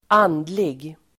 Uttal: [²'an:dlig]